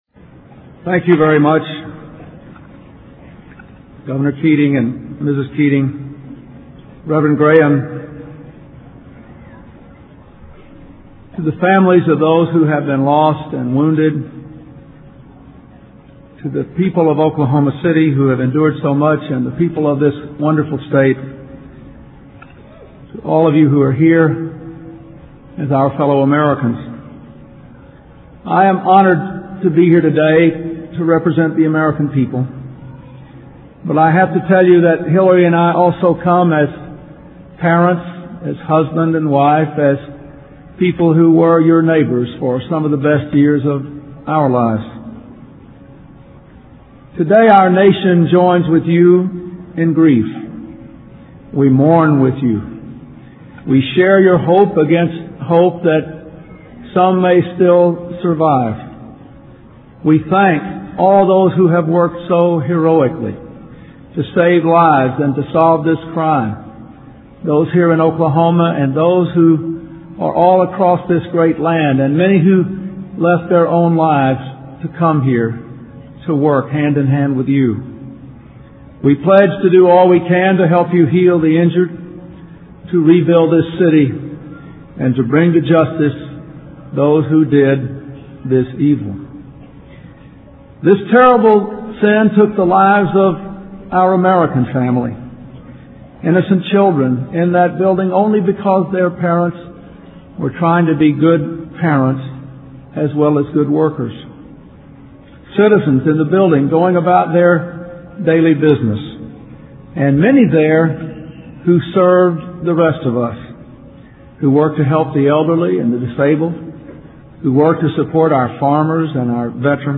Oklahoma Bombing Memorial Prayer Service Address
delivered 23 April 1995 in Oklahoma City, OK